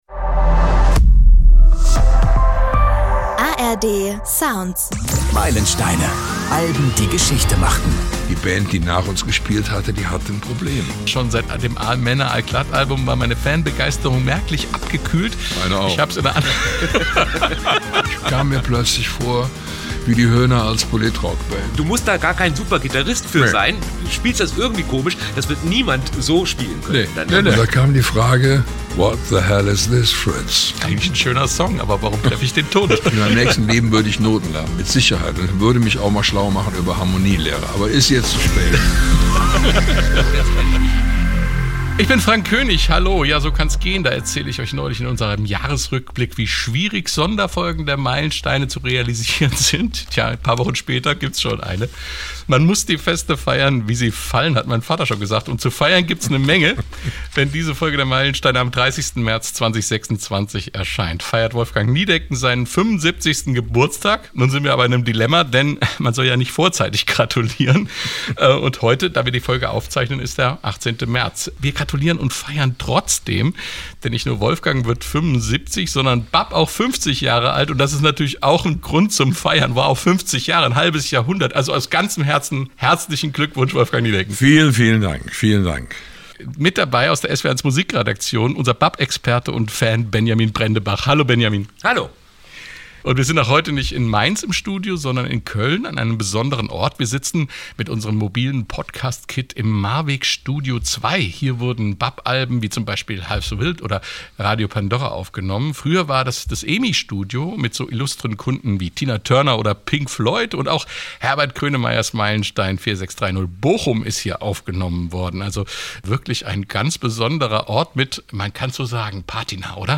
Zur Aufzeichnung haben sich die drei im legendären Maarwegstudio2 in Köln getroffen, in dem auch einige BAP-Alben entstanden sind.